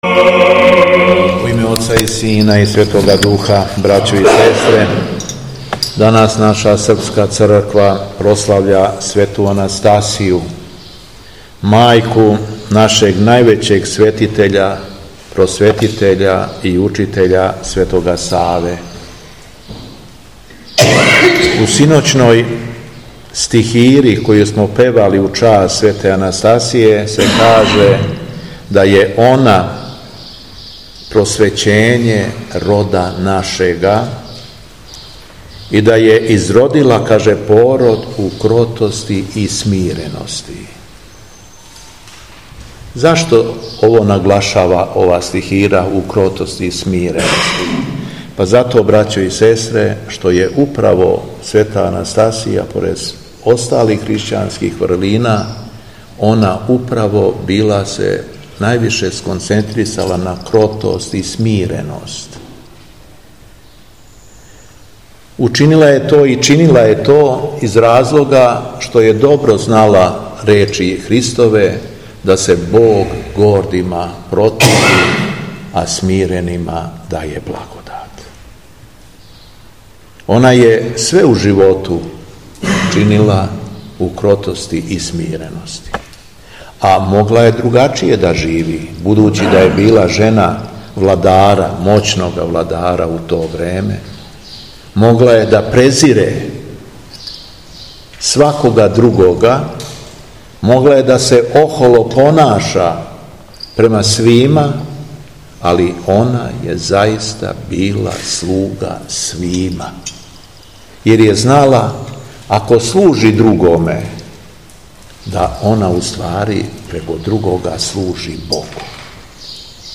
Беседа Његовог Преосвештенства Епископа шумадијског г. Јована
У уторак, 4. јула 2023. године, када наша Света Црква прославља Светог Јулијана Тарсијског и преподобну мати Анастасију Српску, Његово Преосвештенство Епископ шумадијски Господин Јован, служио је Свету Архијерејску Литургију у манастиру Каленић поводом славе манастирске капеле, која је посвећена Светој Анастасији.